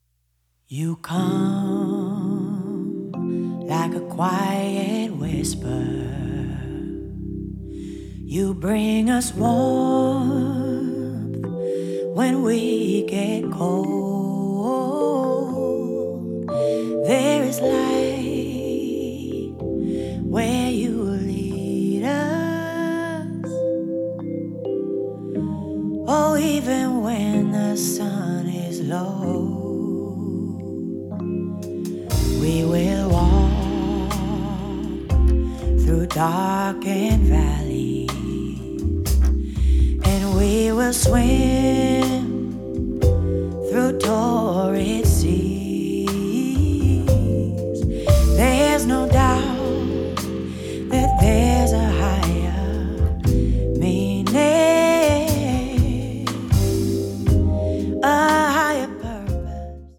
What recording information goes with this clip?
Recorded at Sorriso Studio